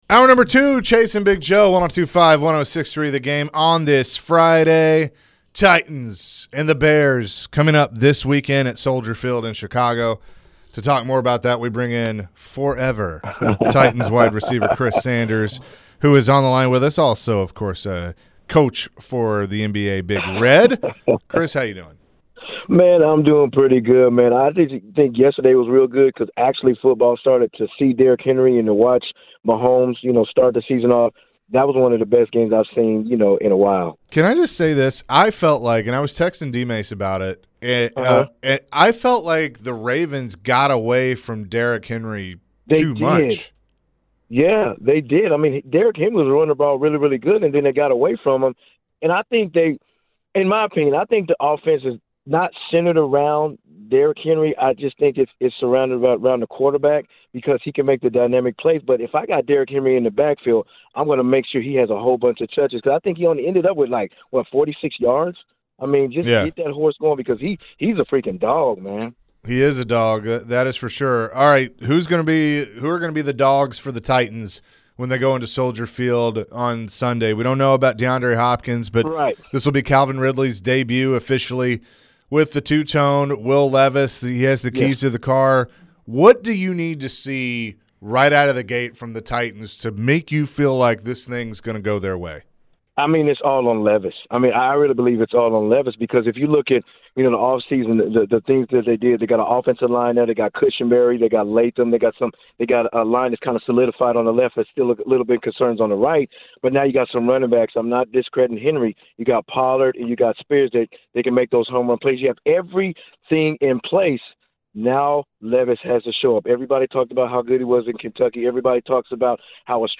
Forever Titans wide receiver Chris Sanders joined the show and shared his thoughts about the upcoming week one matchup against the Bears. Chris shared who’s the most important in their first game of the season.